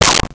Whip 01
Whip 01.wav